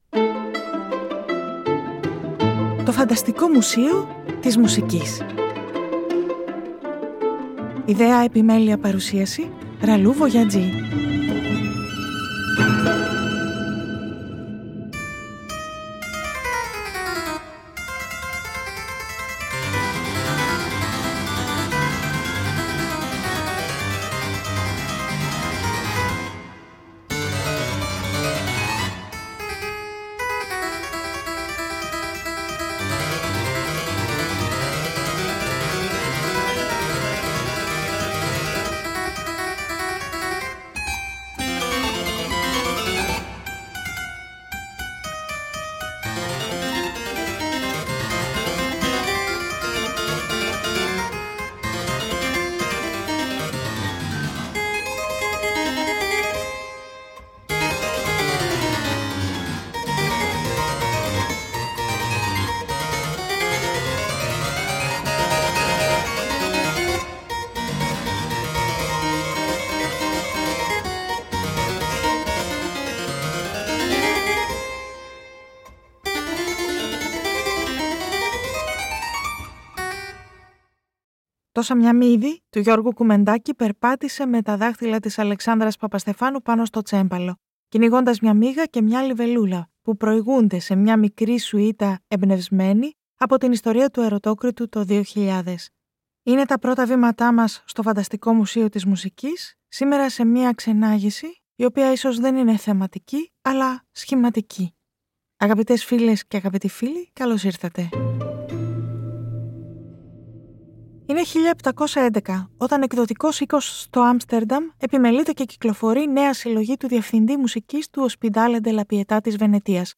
Θα ήταν μια ξενάγηση με επιλογές ελεύθερων συνειρμών. Τελικά η διαδρομή των ακροάσεων σΤο ΦΑΝΤΑΣΤΙΚΟ ΜΟΥΣΕΙΟ της ΜΟΥΣΙΚΗΣ πήρε σχήμα: περιήγηση σε έργα για τσέμπαλο, κιθάρα, φλάουτο…μόνα τους και με ορχήστρα.